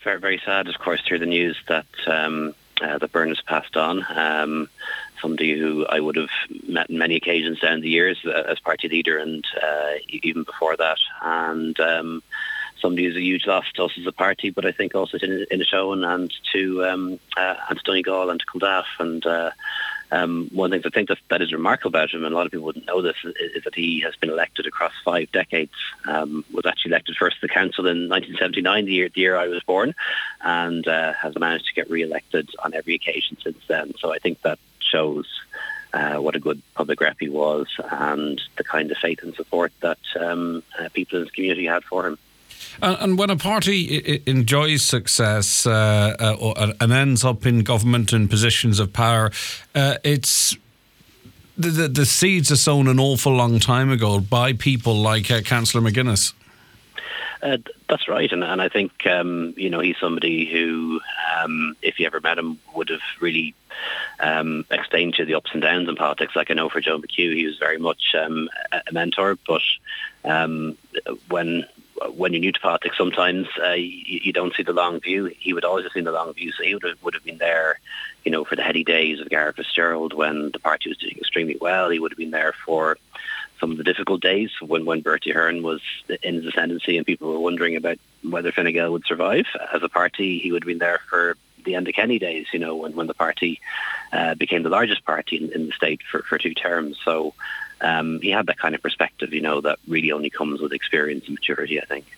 On today’s Nine Til Noon Show, Leo Varadkar said Bernard McGuinness had a perspective on politics that only comes from experience, and was of immense help to younger politicians, not least Deputy Joe McHugh.